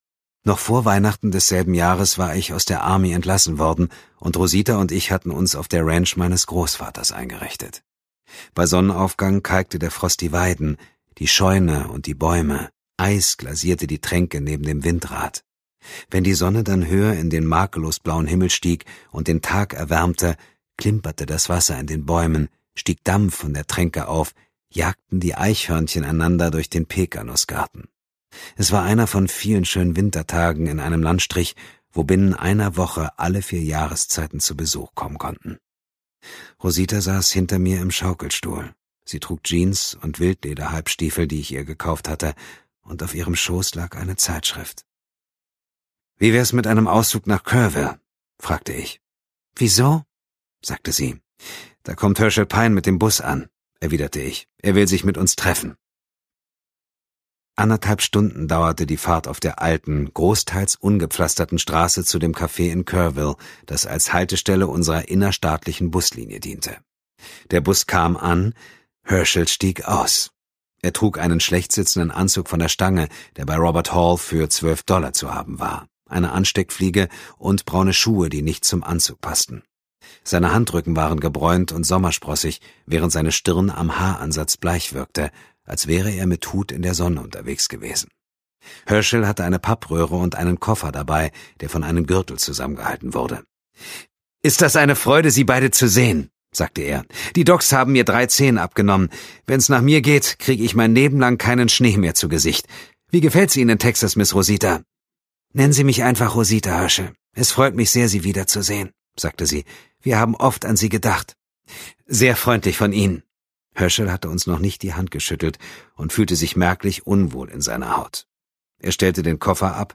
Dietmar Wunder (Sprecher)
2016 | Gekürzte Lesung
Außerdem ist er ein gefragter und exzellenter Hörbuchsprecher, der seine markante Stimme Größen des Thriller-Genres wie Grangé, Larsson und Deaver leiht.